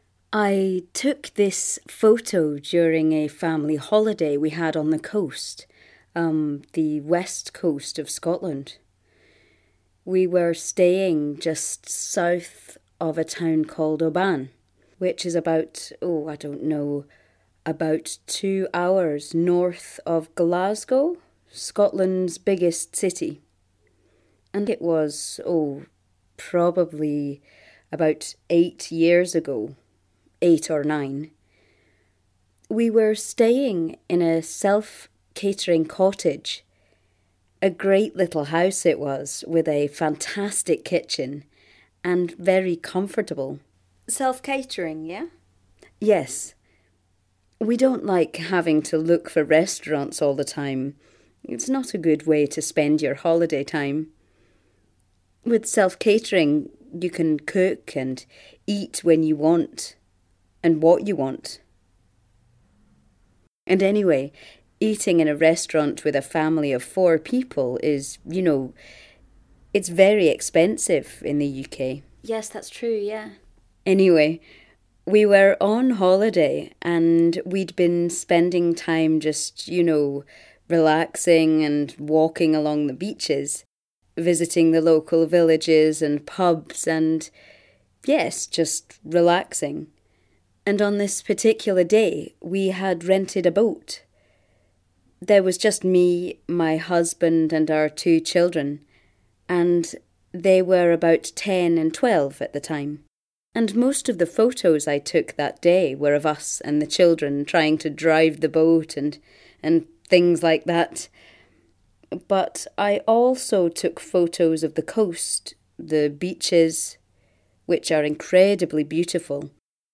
They then listen to a recording of the photographer talking about the photo.